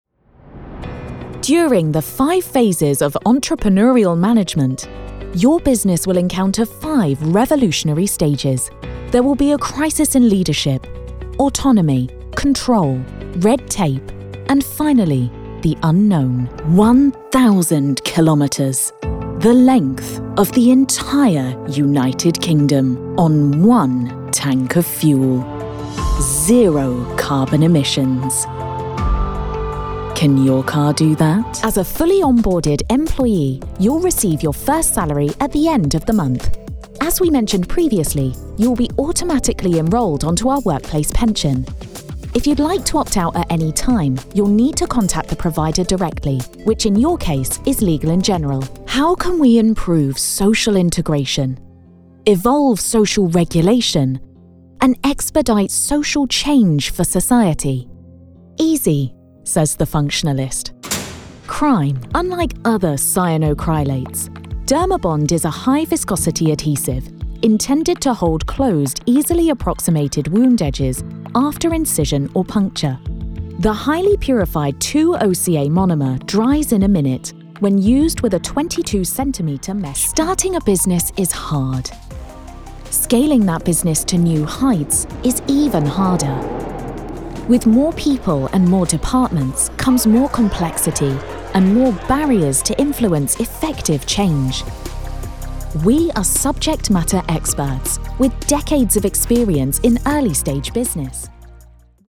English - British and European
General American
Young Adult